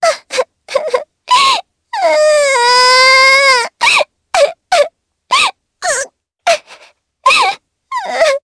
Dosarta-Vox_Sad_jp.wav